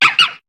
Cri de Ponchiot dans Pokémon HOME.